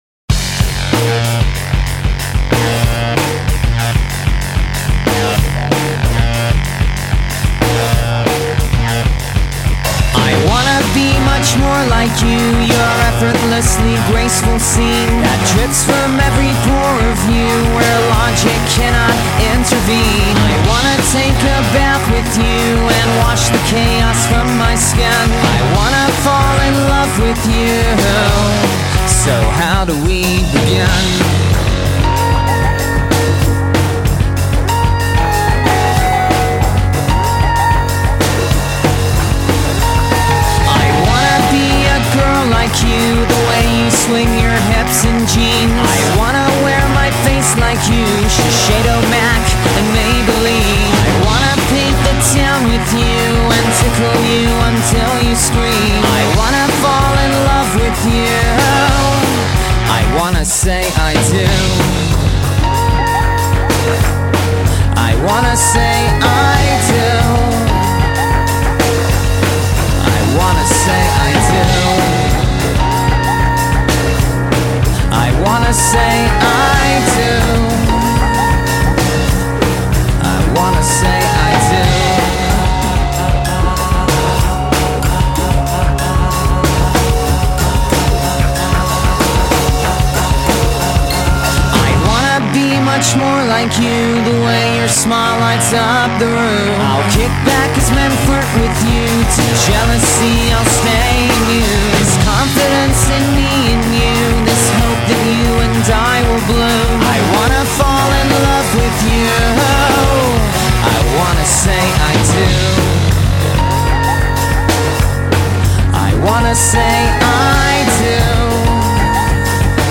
Alternative rock Indie rock